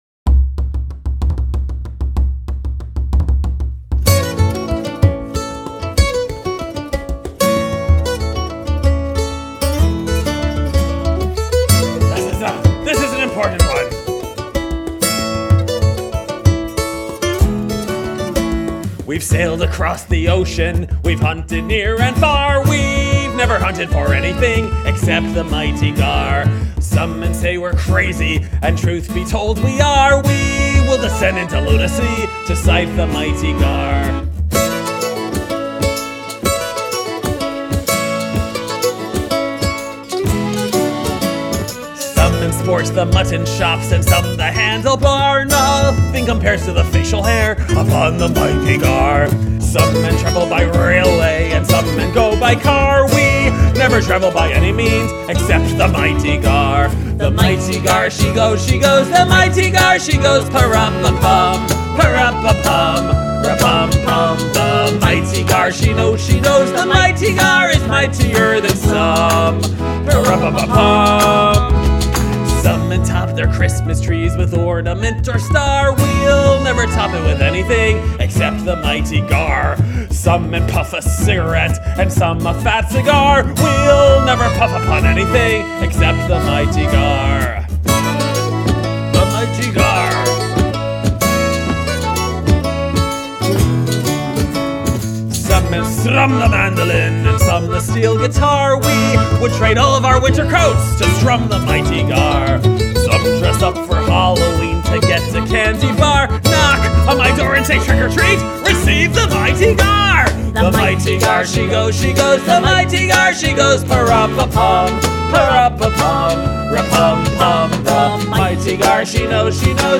additional vocals
flute